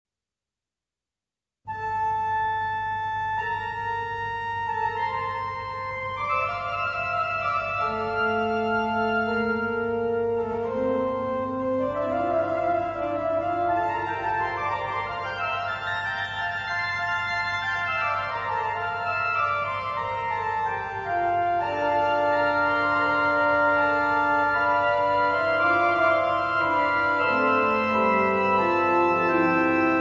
organo